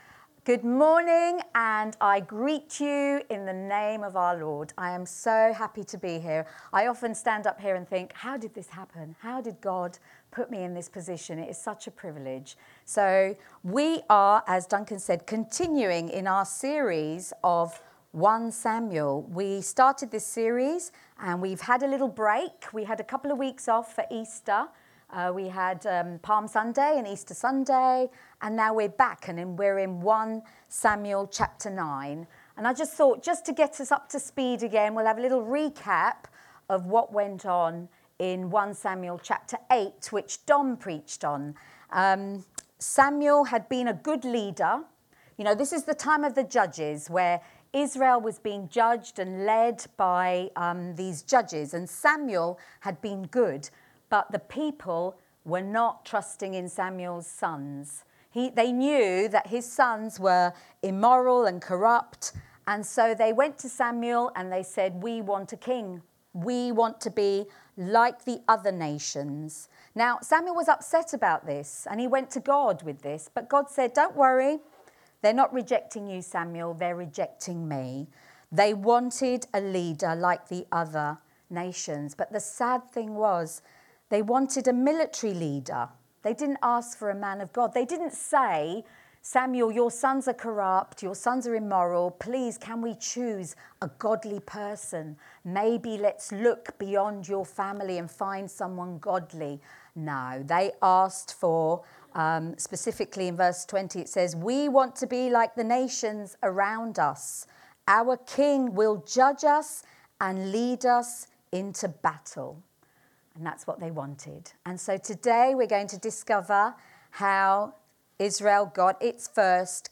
Download Finding a king | Sermons at Trinity Church